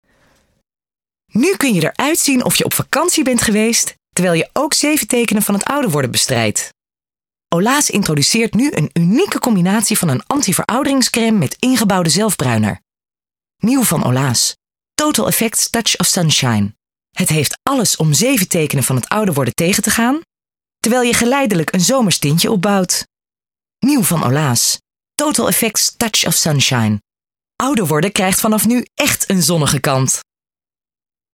Commercials:
Oil of Olaz (vriendelijk):